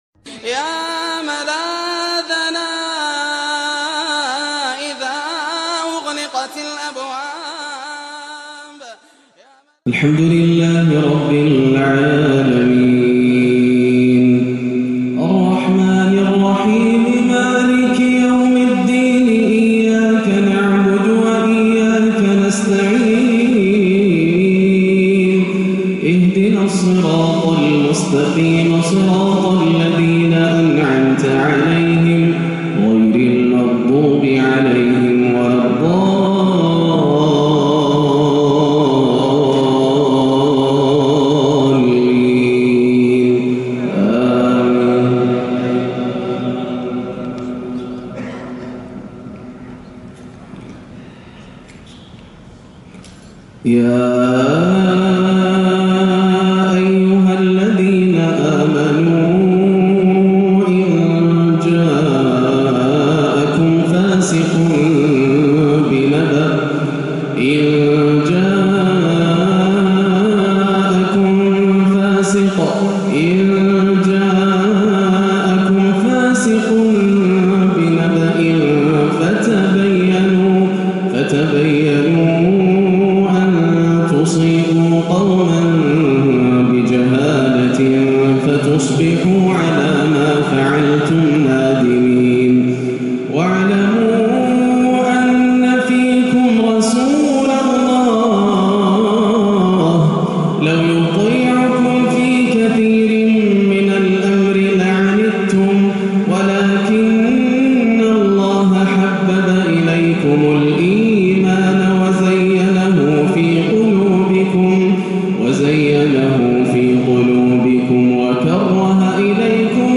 صلاة العشاء 7-2-1438هـ أواخر سورة الحجرات 6-18 > عام 1438 > الفروض - تلاوات ياسر الدوسري